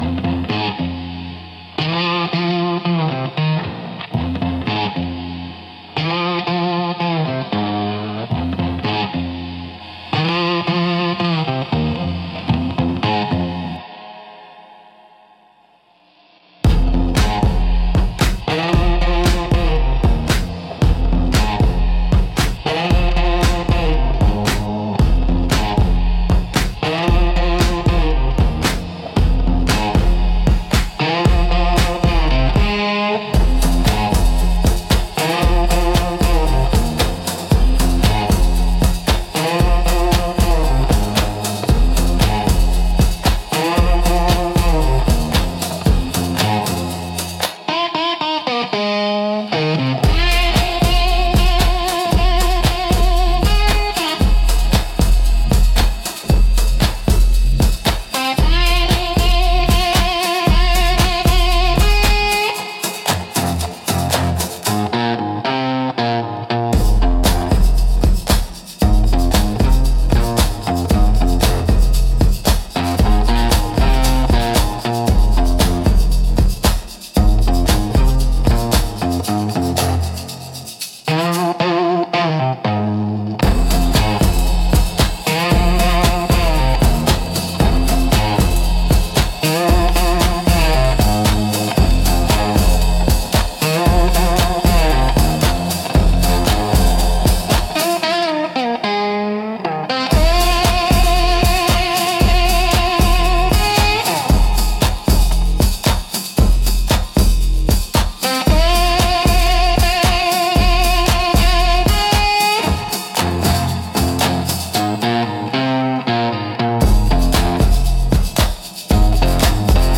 Instrumental - Country Blues x Sub-Bass 3.02